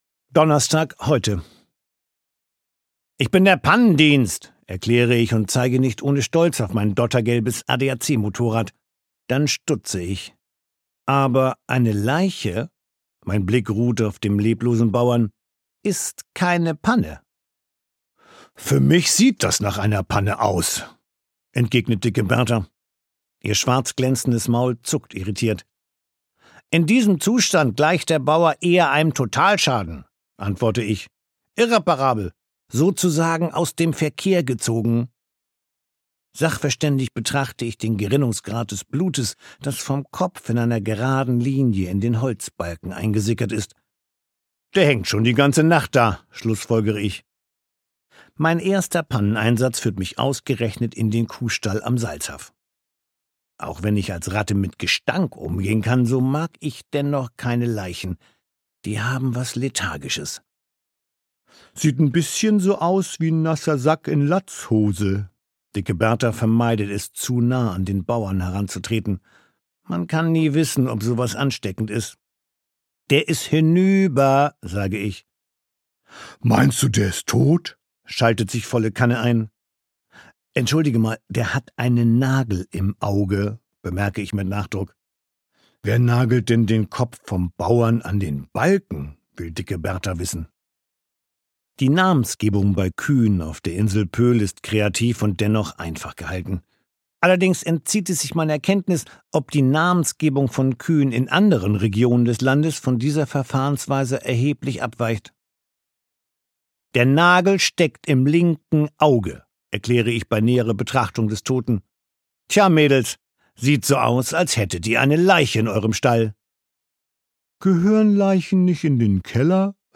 Kommissar Ratte ermittelt - Benjamin Tomkins - Hörbuch - Legimi online